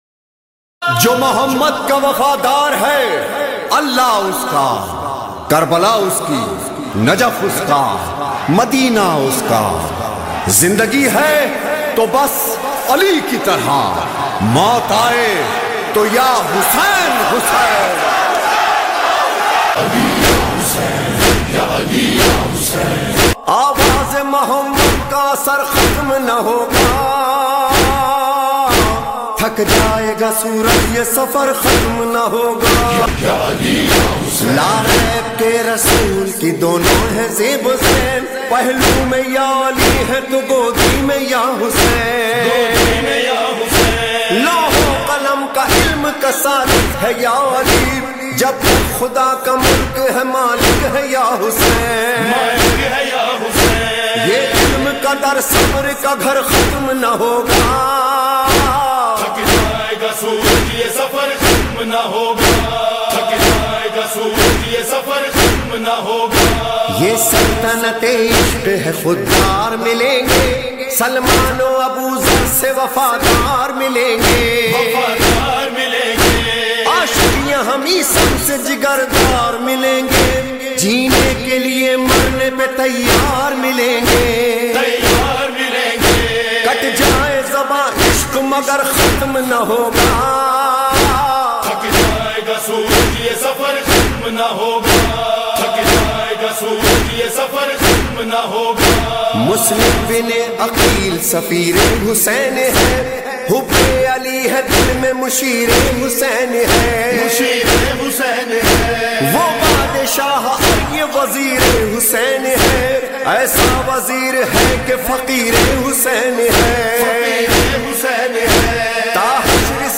Full Noha